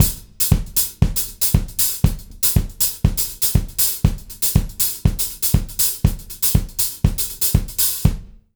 120ZOUK 07-R.wav